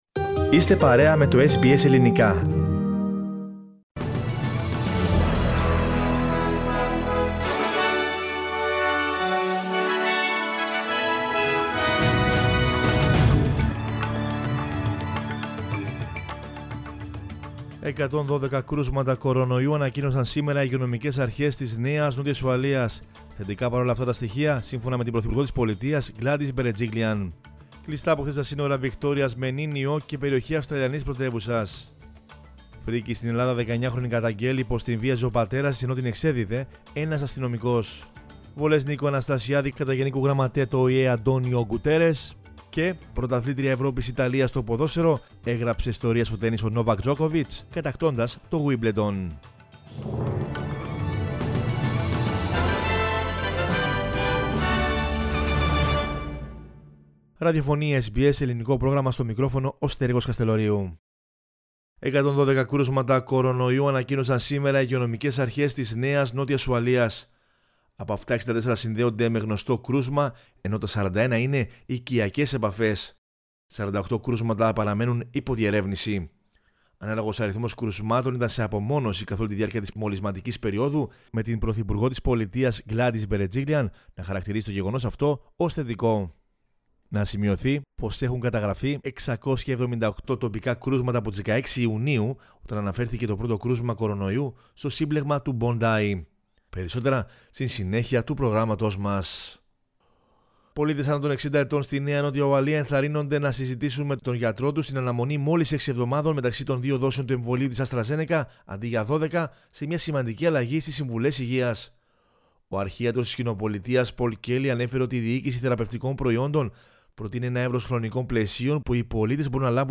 News in Greek from Australia, Greece, Cyprus and the world is the news bulletin of Monday 12 July 2021.